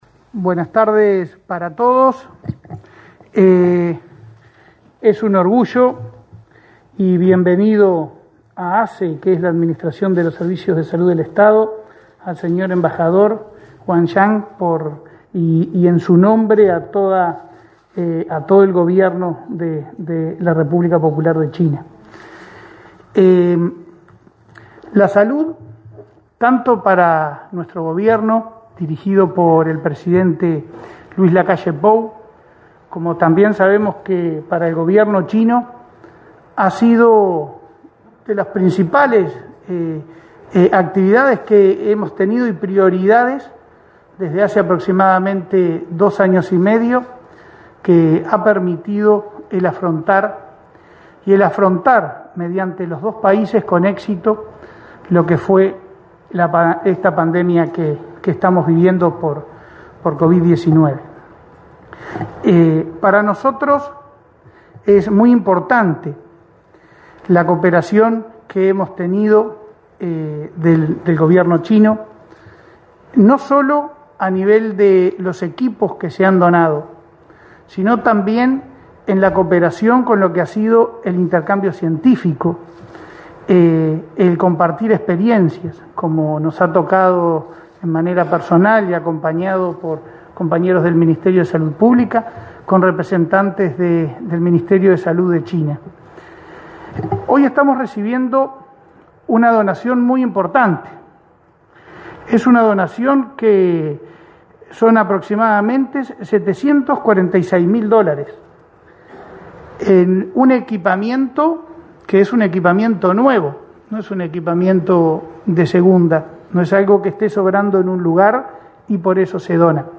Conferencia de prensa por donación de equipamiento de salud de la República Popular de China
Participaron del evento, el presidente de ASSE, Leonardo Cipriani; el director general de Salud, Miguel Asqueta; el embajador de China, Wang Gang; la subsecretaria de Cancillería, Carolina Ache, y el prosecretario de Presidencia y presidente de AUCI, Rodrigo Ferrés.